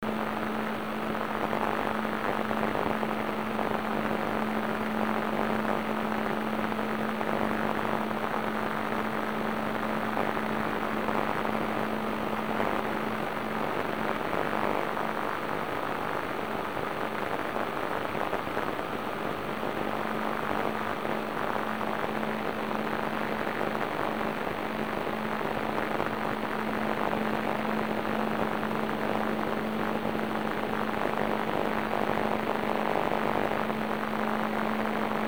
Сигнал очень сложен, и в отличие от РВМ, по нему особо не синхронизируешься на слух... Нужен дешифратор (программный).
На пределе слышмости можно послушать сигнал, скачав
77.5kHz_DCF77.mp3